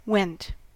Ääntäminen
IPA : /ˈwɛnt/